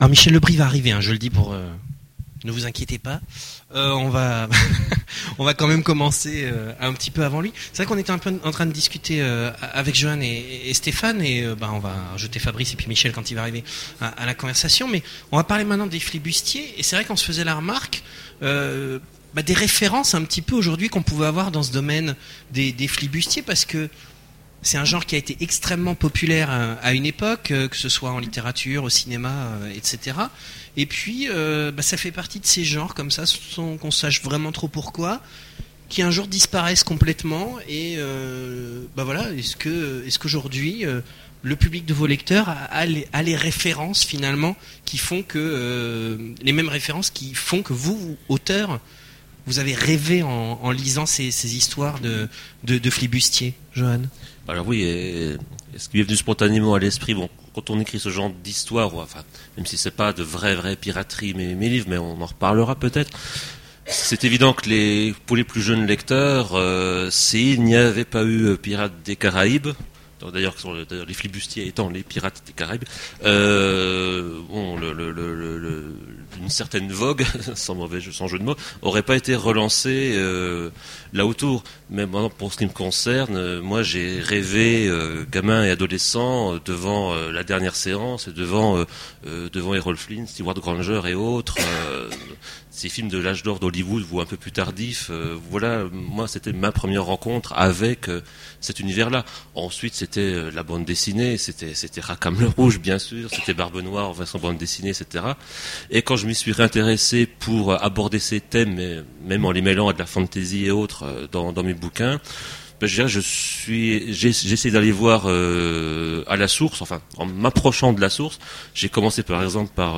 Voici l'enregistrement de la conférence Pirates et flibustiers… aux Imaginales 2010